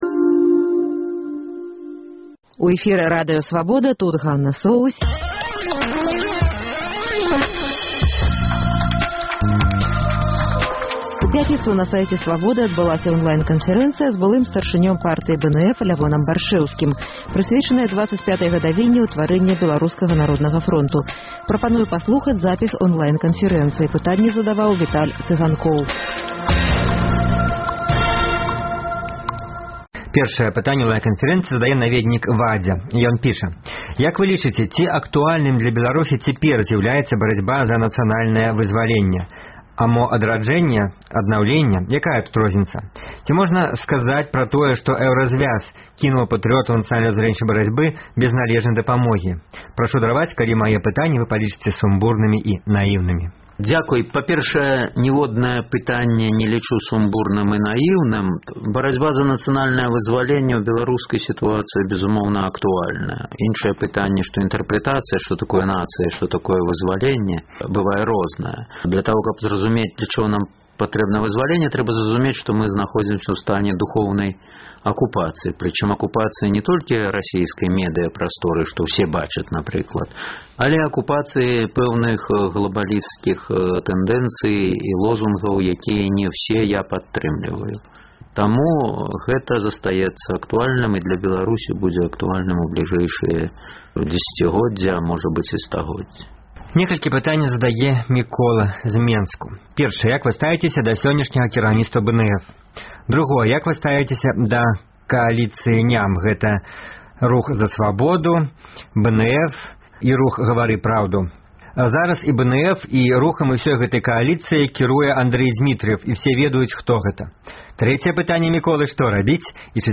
Былы старшыня БНФ Лявон Баршчэўскі адказвае на вашыя пытаньні ў эфіры і на сайце Свабоды.